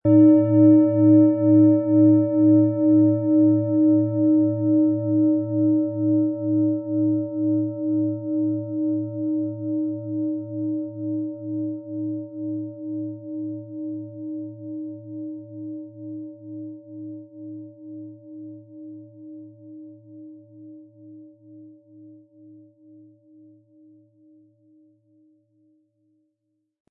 Planetenton
Die Schale mit Biorhythmus Seele, ist eine in uralter Tradition von Hand getriebene Planetenklangschale.
Der richtige Schlegel ist umsonst dabei, er lässt die Klangschale voll und angenehm erklingen.
MaterialBronze